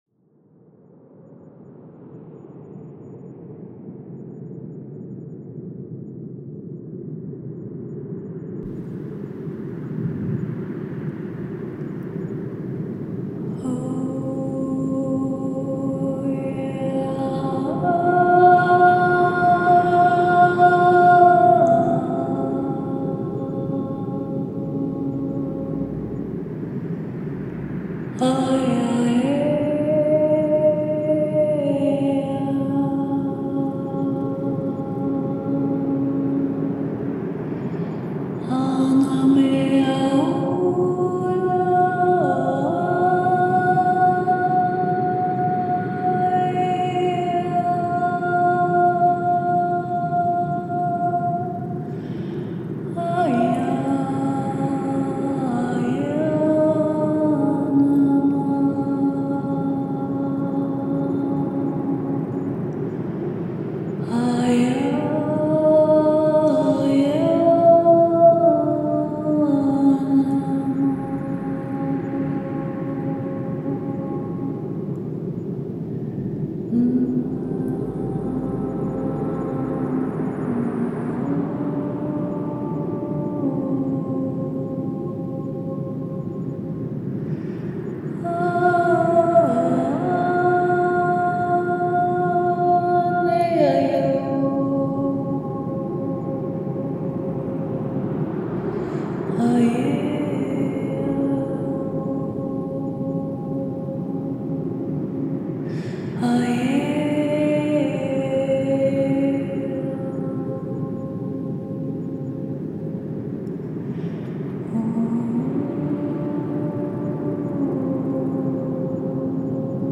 Soundmeditation zur Stärkung des Yin
Video/Sound: Pixabay
Stimme_des_Nordens.mp3